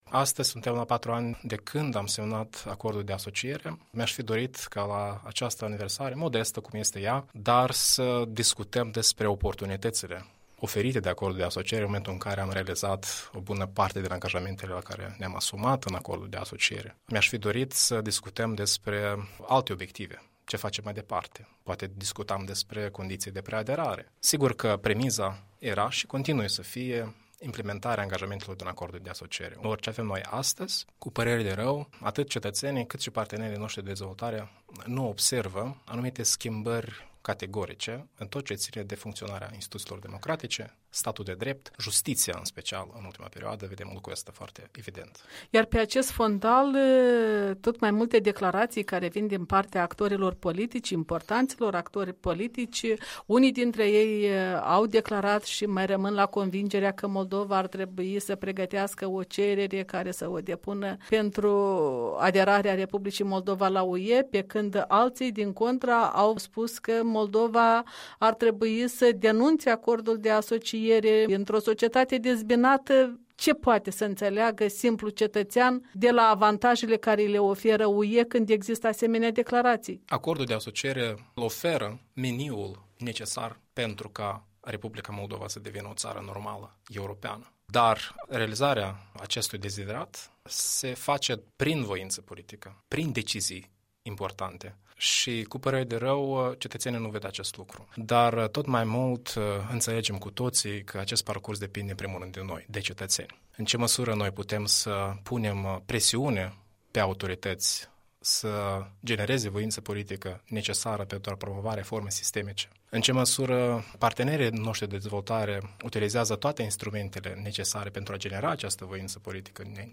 Un interviu cu directorul Institutului de Politici şi Reforme Europene, fost ministru adjunct de externe.